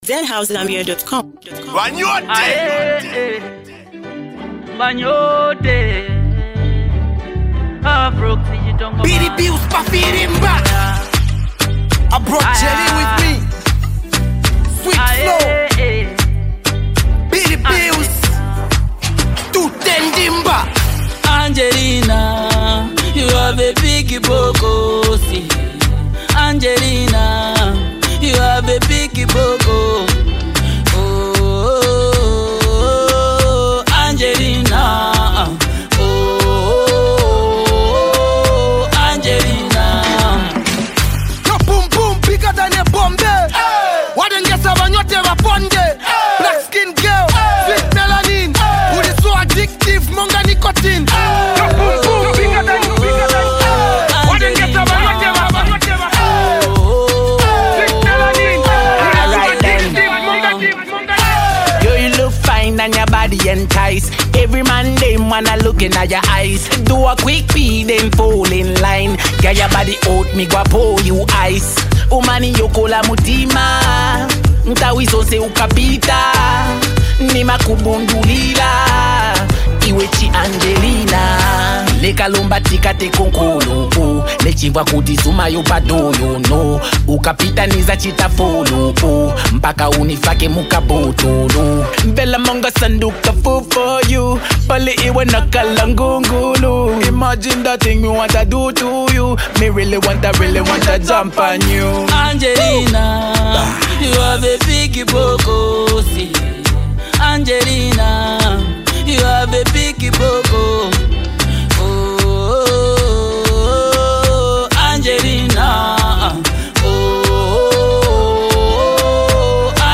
smooth Afro Dancehall jam